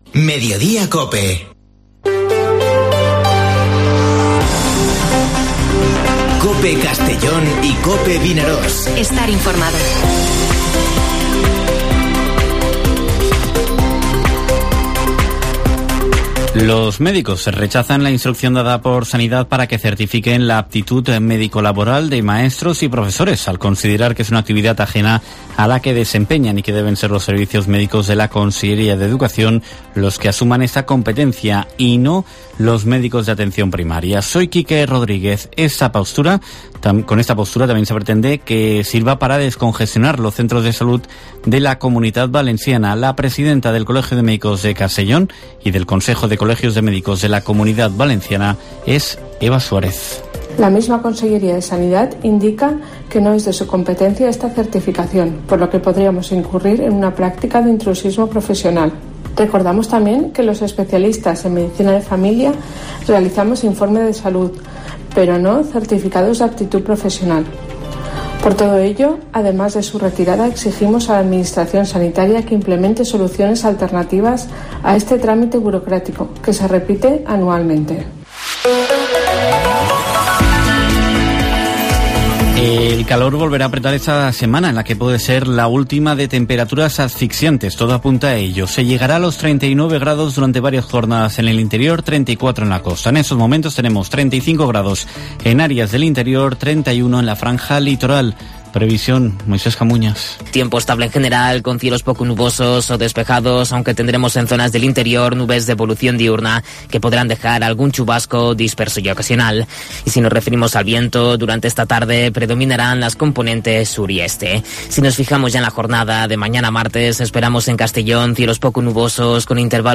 Informativo Mediodía COPE en la provincia de Castellón (08/08/2022)